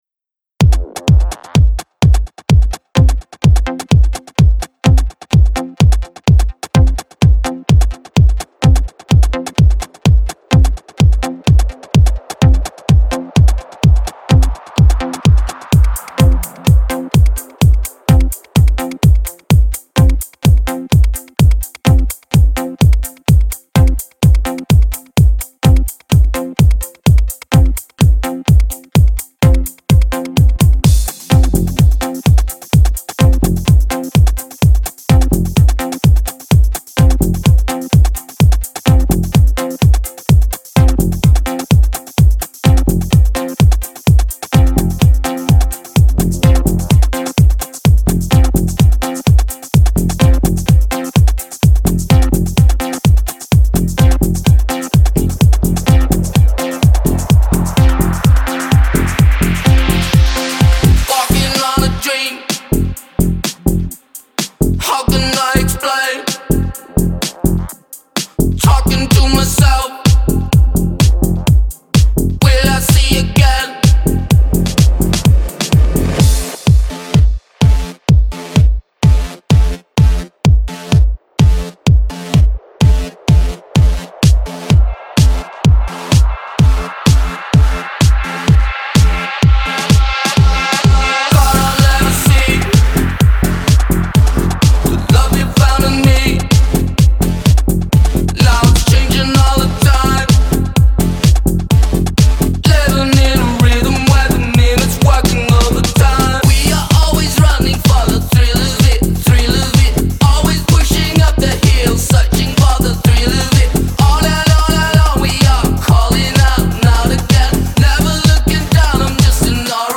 psychedelic pop-rock